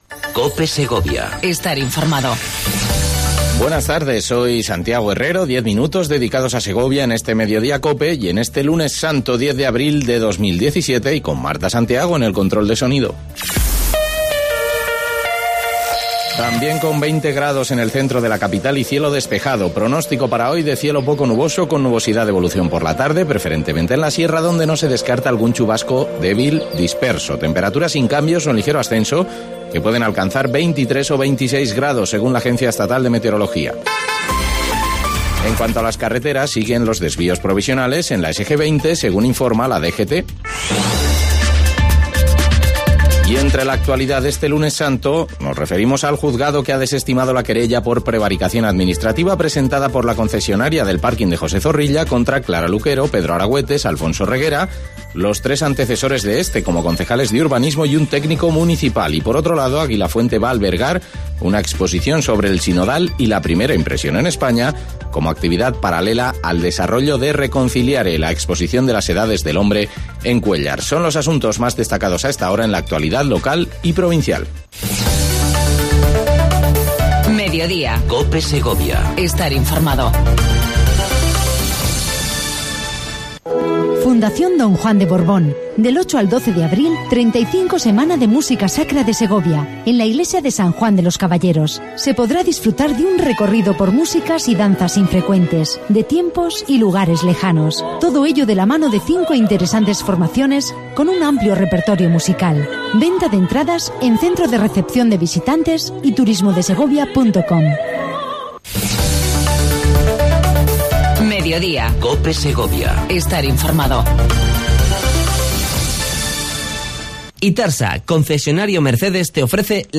ENTREVISTA CON CLARA LUQUERO